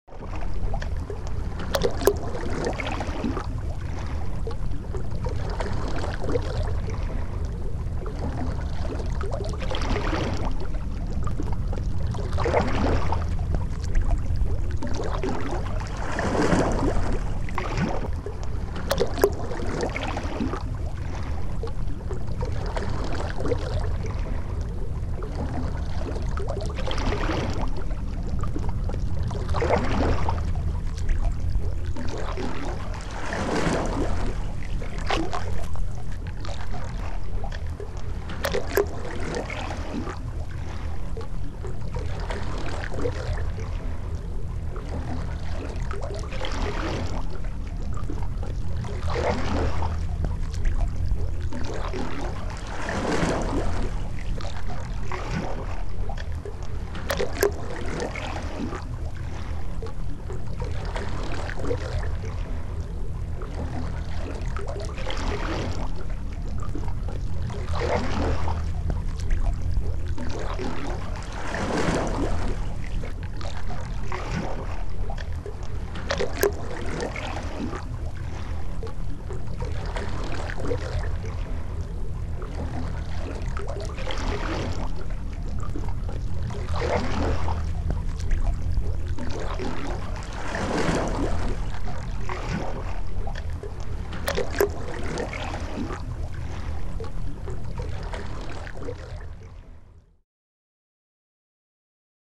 دانلود آهنگ وال 24 از افکت صوتی انسان و موجودات زنده
جلوه های صوتی
برچسب: دانلود آهنگ های افکت صوتی انسان و موجودات زنده دانلود آلبوم صدای حیوانات آبی از افکت صوتی انسان و موجودات زنده